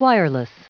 Prononciation du mot wireless en anglais (fichier audio)
Prononciation du mot : wireless